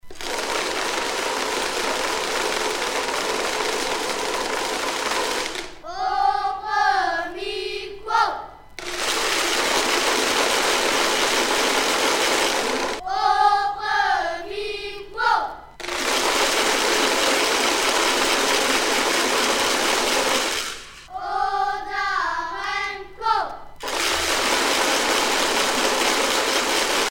Crécelles de la Semaine Sainte à Chatillon